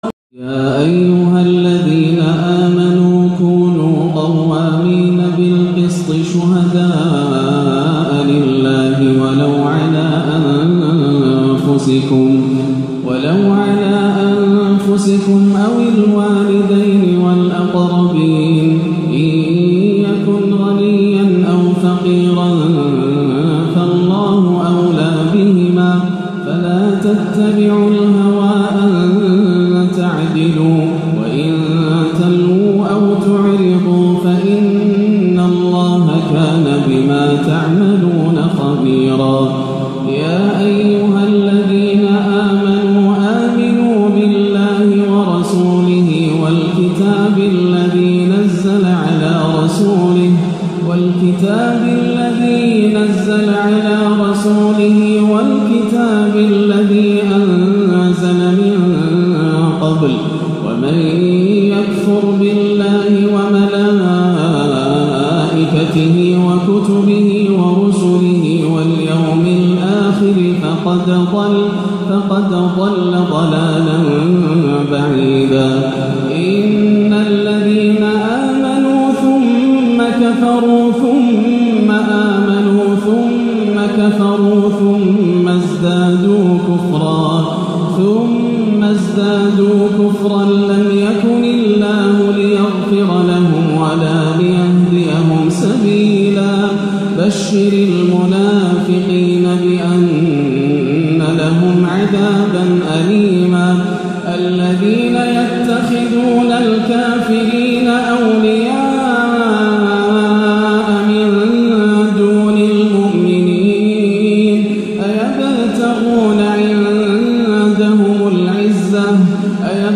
(إِنَّ الْمُنَافِقِينَ فِي الدَّرْكِ الْأَسْفَلِ مِنَ النَّارِ) يعود شيخنا لأسلوبه المؤثر بالصبا - السبت 7-8 > عام 1437 > الفروض - تلاوات ياسر الدوسري